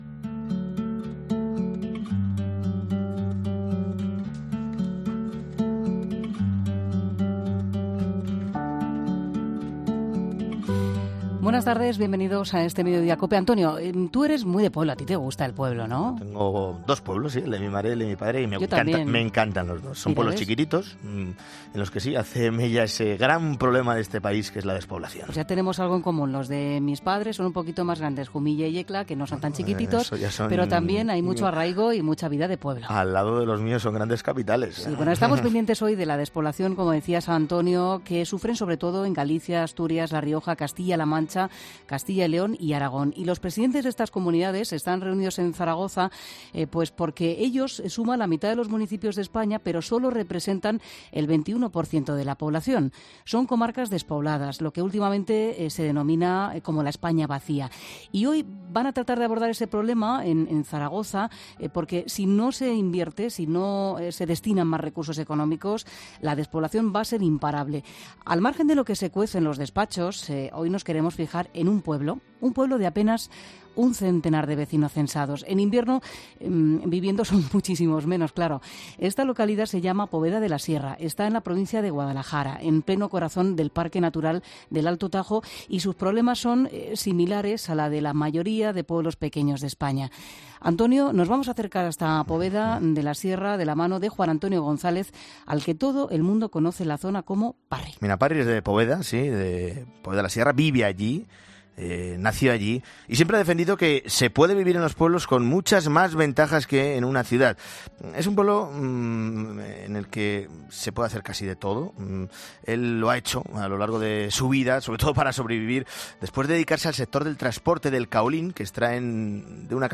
Al margen de lo que se cuece en los despachos, en 'Mediodía COPE' nos hemos ido a un pueblo de apenas un centenar de vecinos censados.